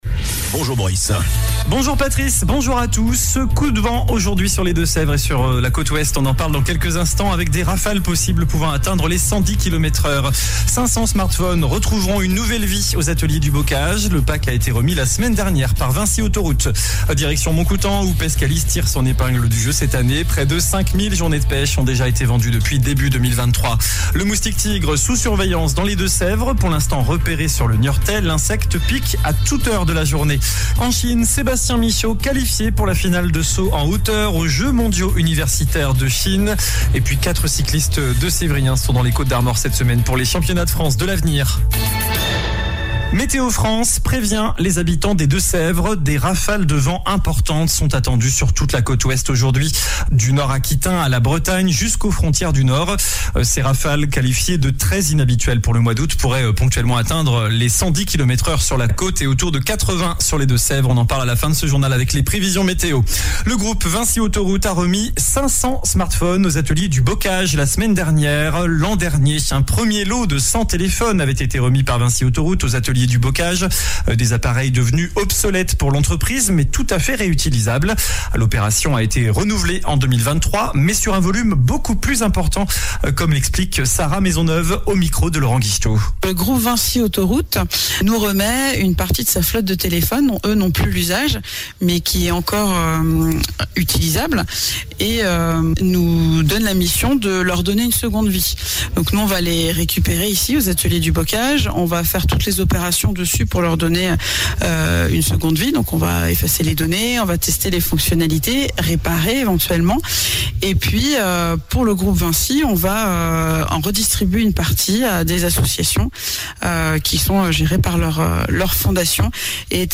JOURNAL DU MERCREDI 02 AOÛT ( MIDI )